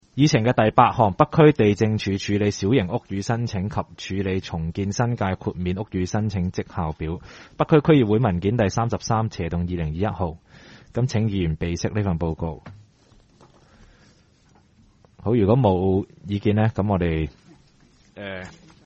区议会大会的录音记录
北区区议会会议室